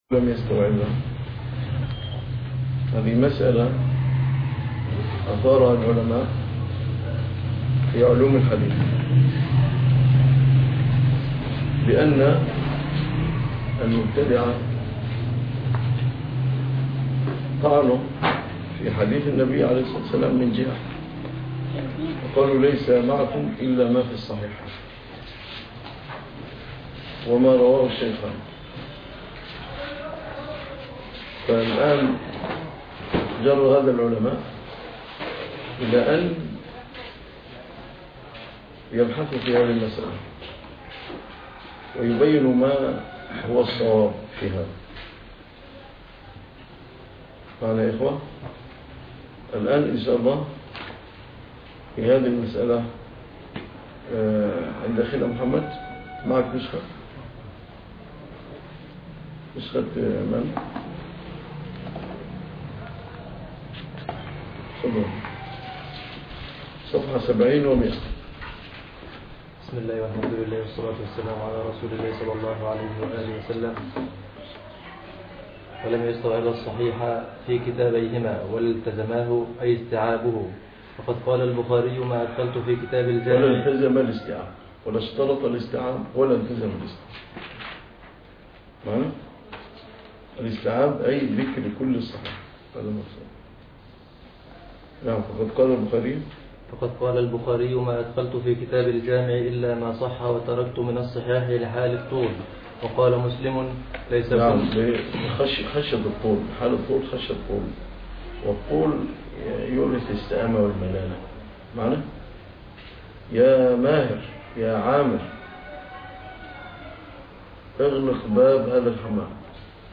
الدرس 011